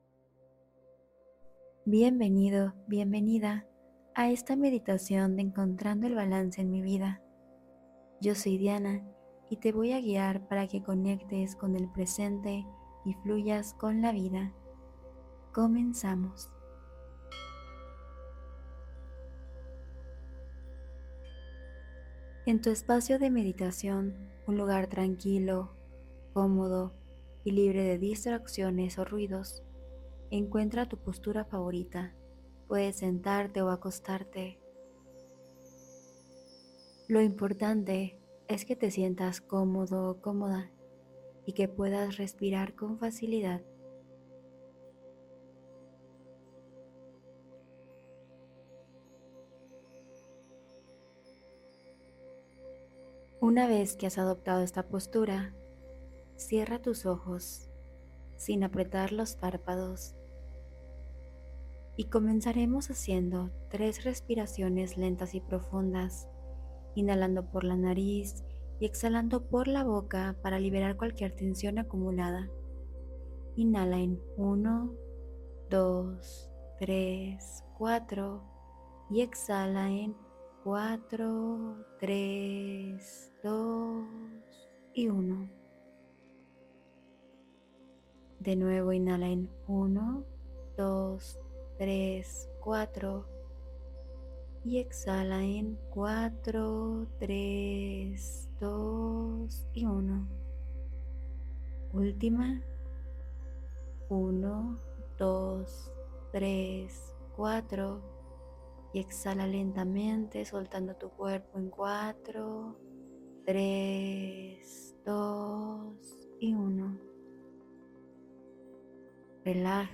Fluye con la Vida: Meditación Guiada de Aceptación y Rendición ✨